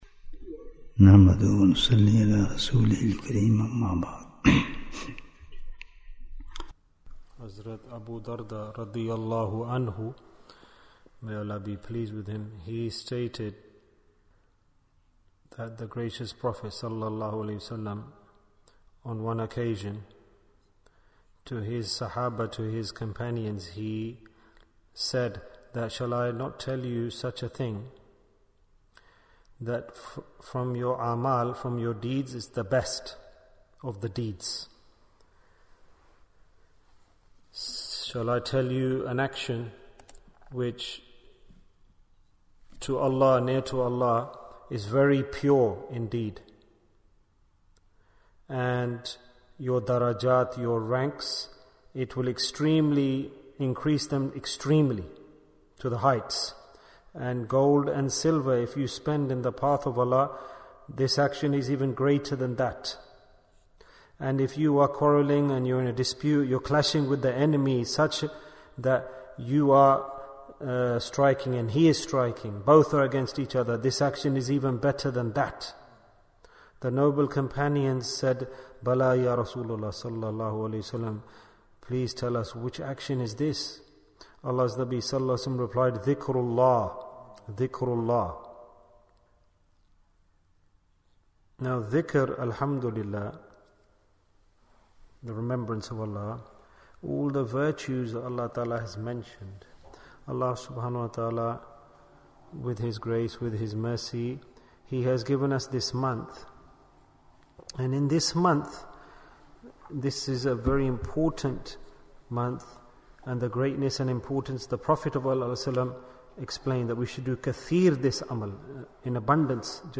The Importance of Dhikrullah in Ramadhan Bayan, 30 minutes28th March, 2023